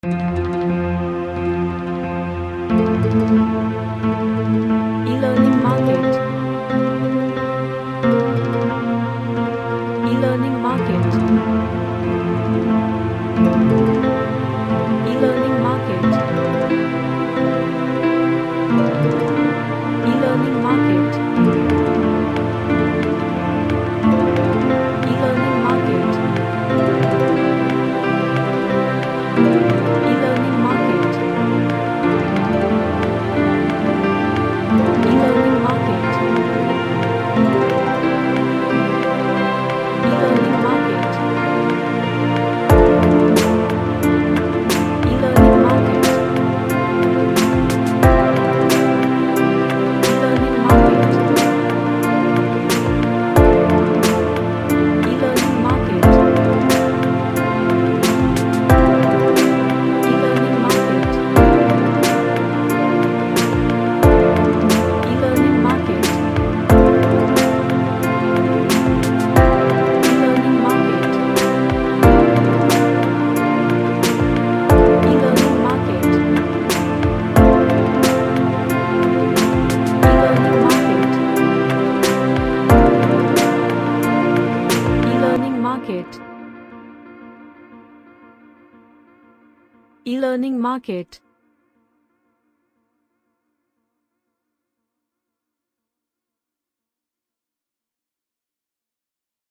A Relaxing soothing track.
Relaxation / Meditation